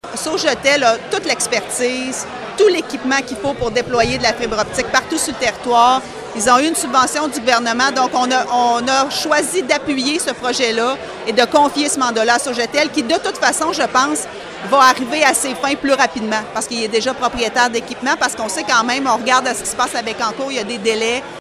Comme l’explique la préfète Geneviève Dubois, le modèle de la MRC de Bécancour, qui est propriétaire de son réseau, a été envisagé, mais n’a pas été retenu :